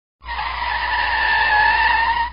skid.wav